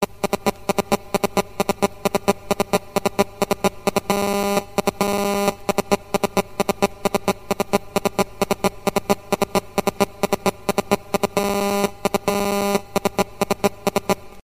gsm-speaker_25314.mp3